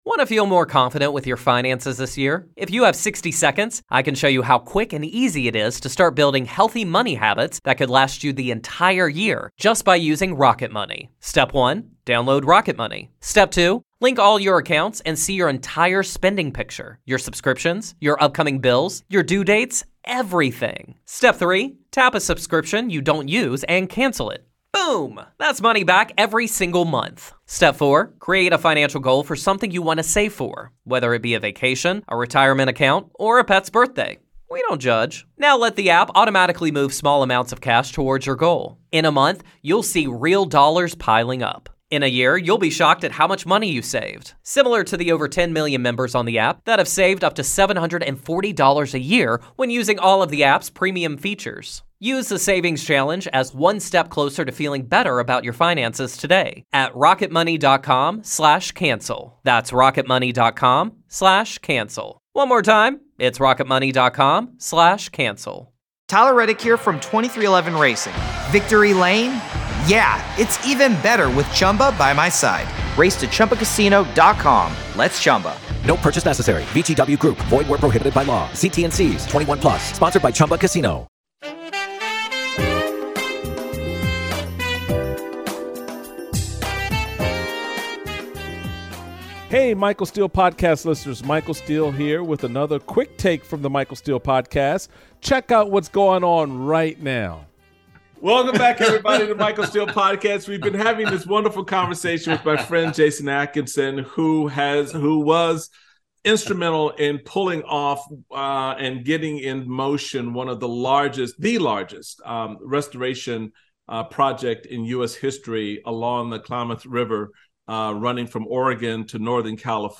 Michael Steele speaks with filmmaker and former Oregon State Senator, Jason Atkinson, about the organization Pastor Mondays, which merges faith, community and fly fishing to curate fly fishing experiences for pastors.